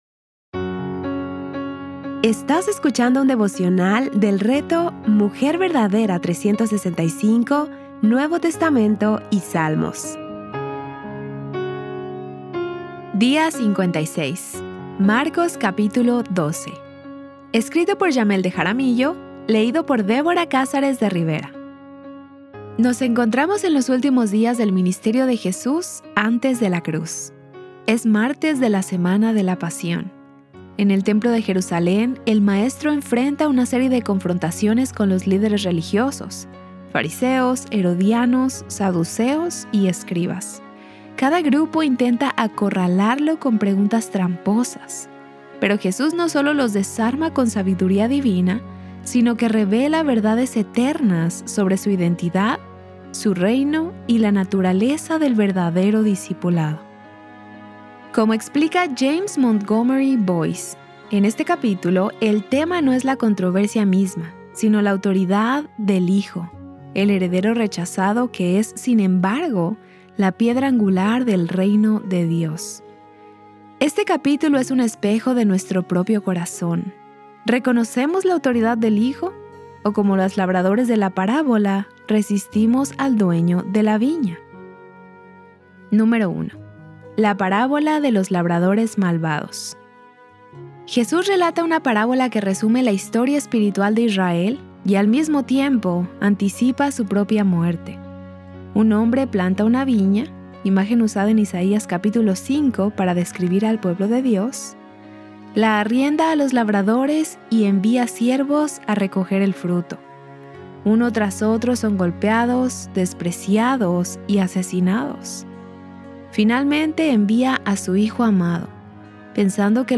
Series:  Marcos y Salmos | Temas: Lectura Bíblica